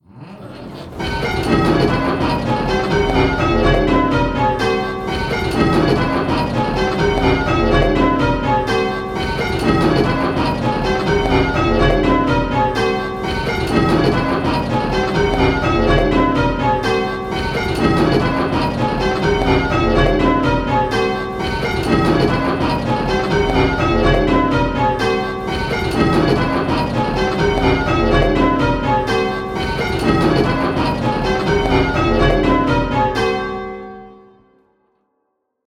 Striking 8 Bell Rounds - Pebworth Bells
Striking 8 Bell Rounds - Round 2